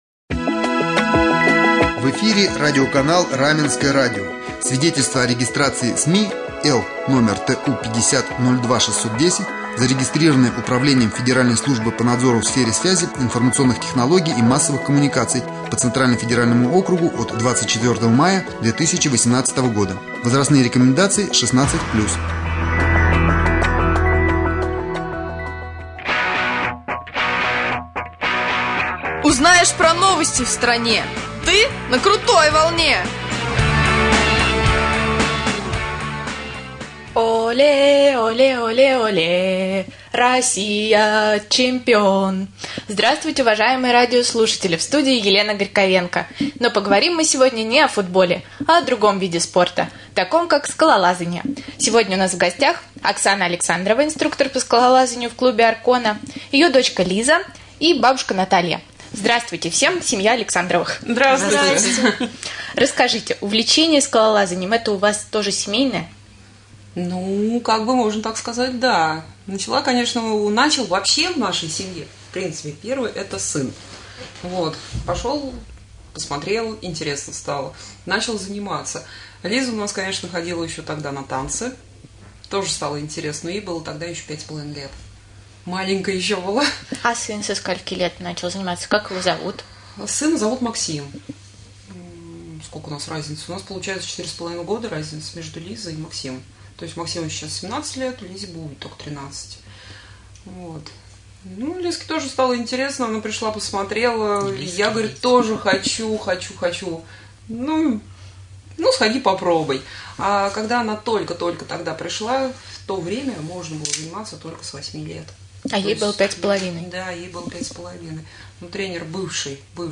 Na-krutoj-volne-2-ijulja-skalolazanieflejta-pana.mp3